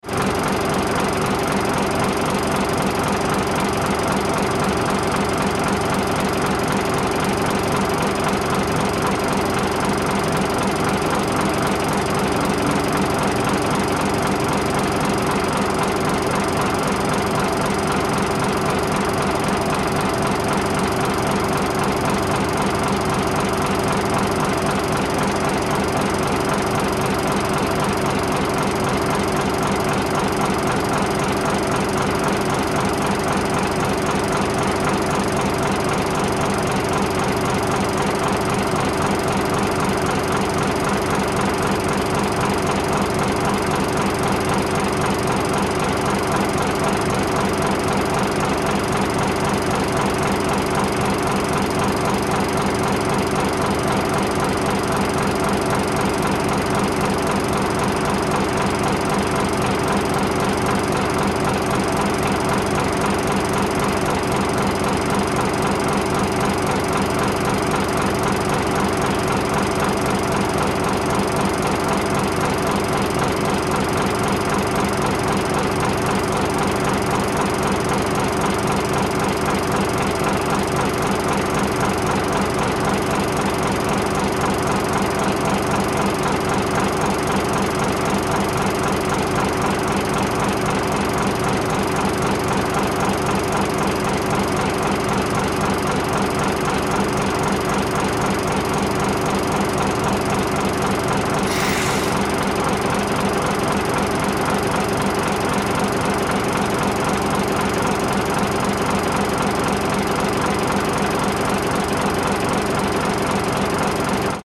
Шум двигателя автокрана